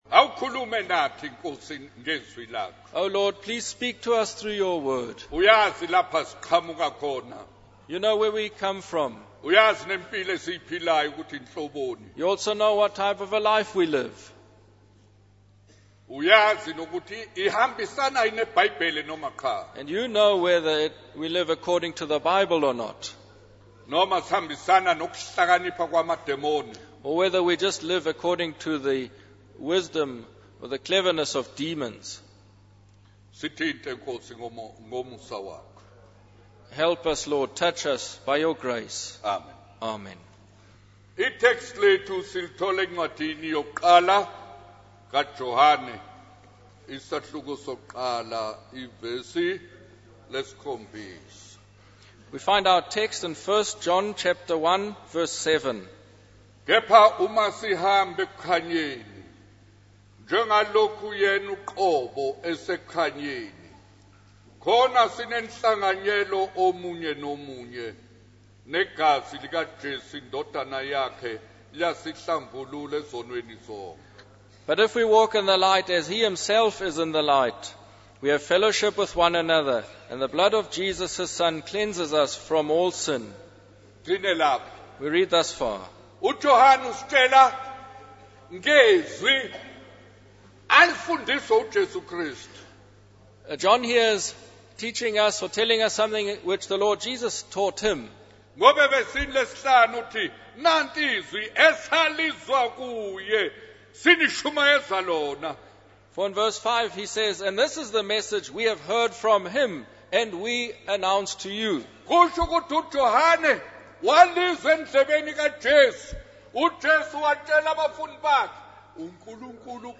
In this sermon, the speaker emphasizes the importance of passing on the teachings of Jesus to others. He uses the analogy of water flowing in and out to illustrate the concept of receiving and sharing God's message.